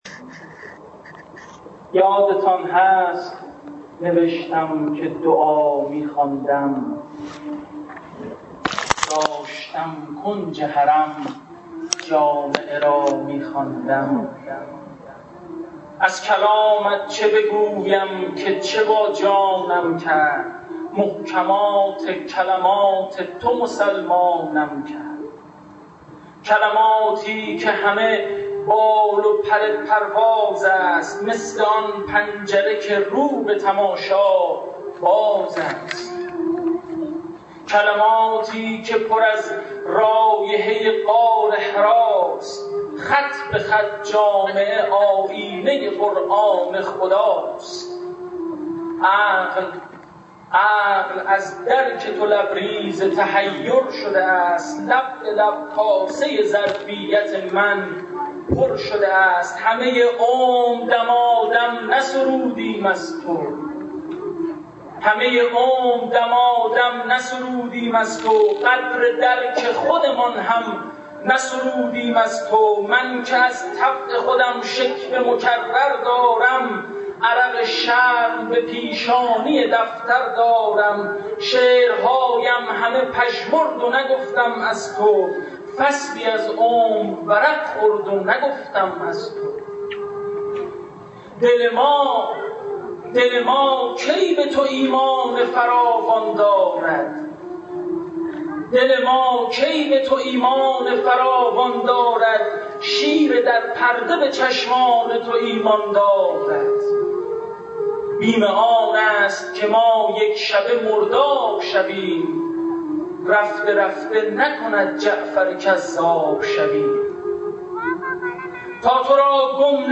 در سوگواره «سلسله موی دوست» که شب گذشته در شام غریبان امام هادی علیه‌السلام از سوی سازمان فرهنگی هنری شهرداری تهران در تالار وزارت کشور برگزار شد